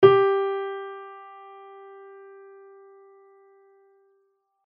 Added sound samples